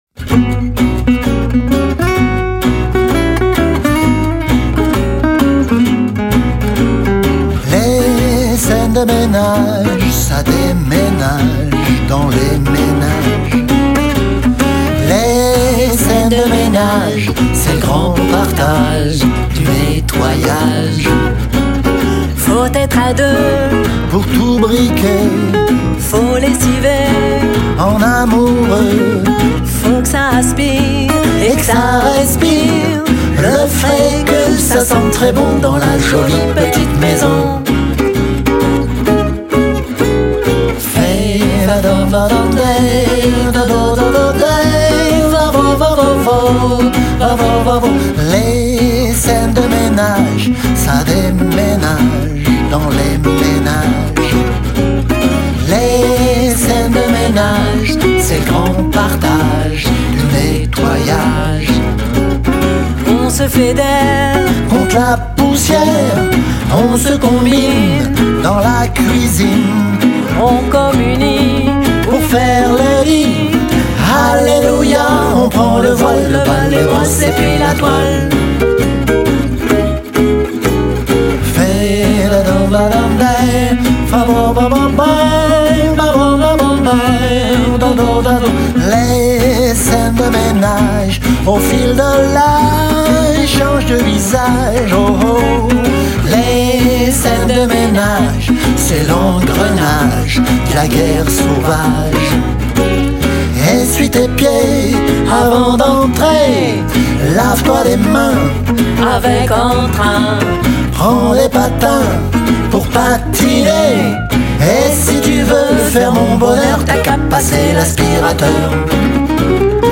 Si vous aimez le swing
les guitares